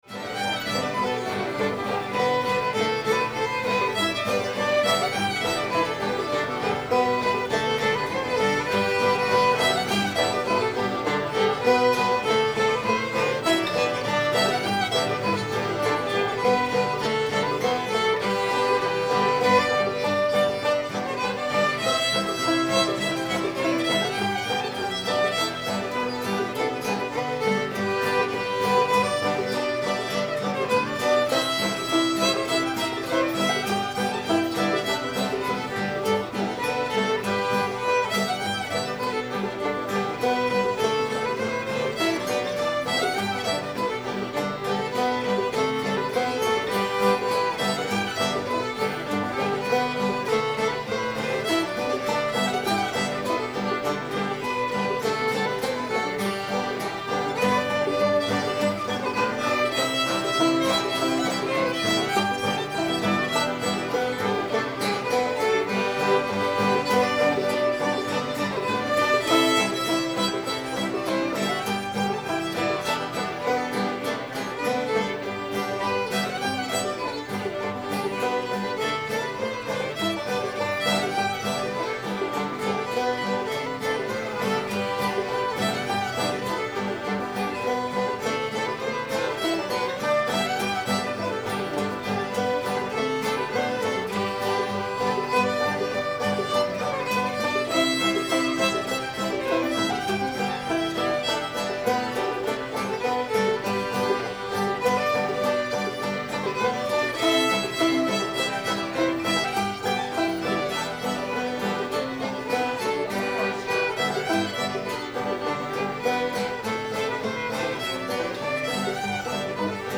cuffy [G]